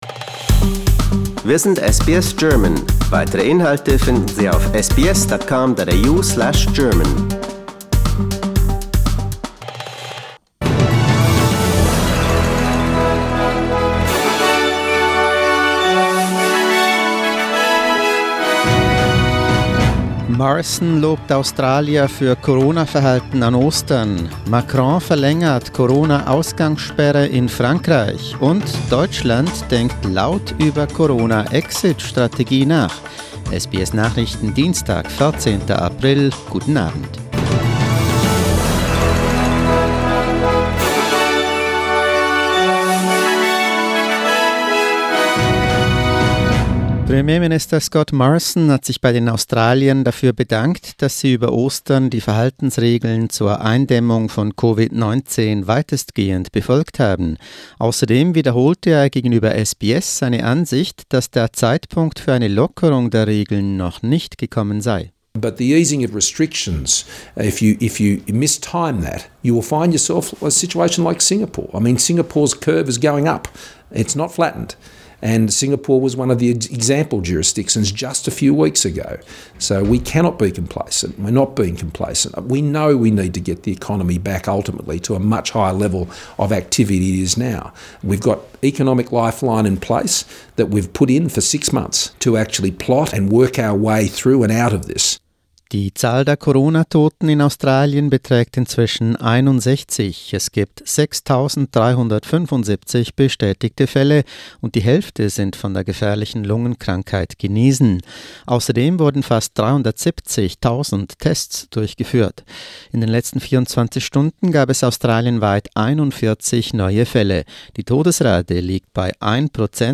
SBS Nachrichten, Dienstag 14.04.20